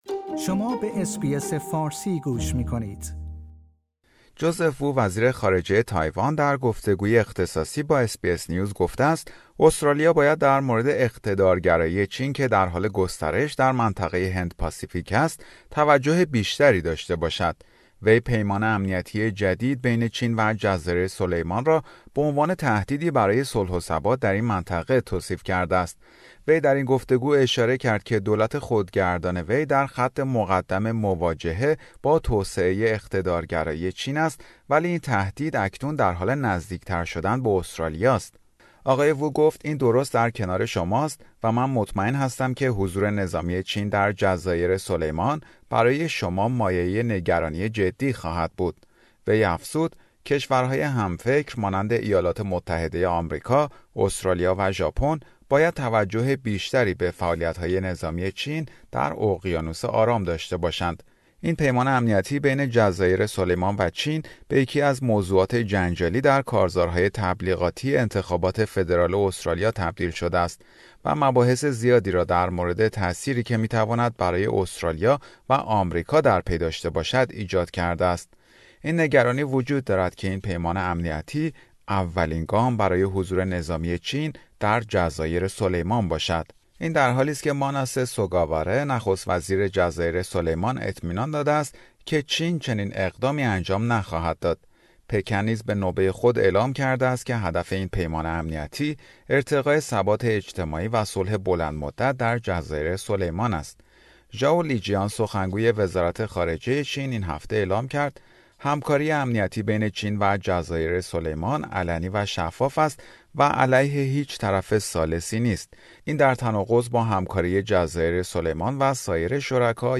وزیر خارجه تایوان در گفتگوی اختصاصی با SBS: استرالیا به گسترش اقتدارگرایی چین بیشتر توجه کند